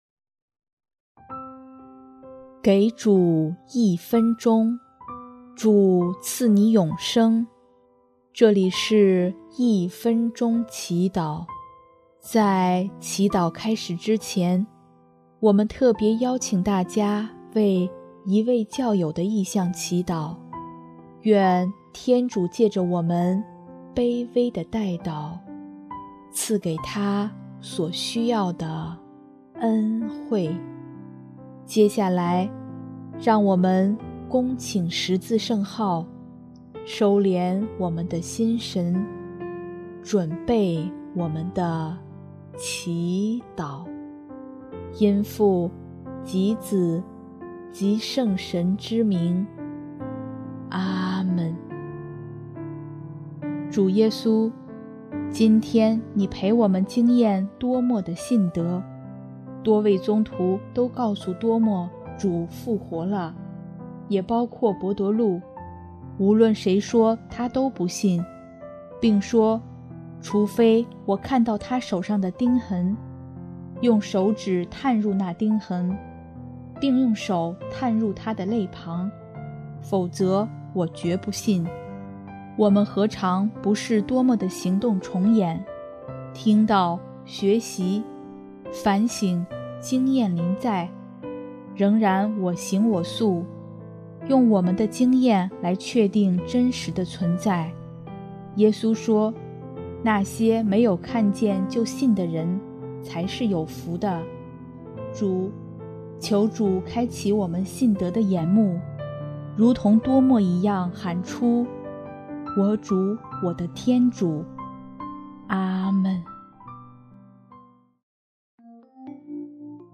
音乐： 第一届华语圣歌大赛获奖歌曲《我的生命你的爱》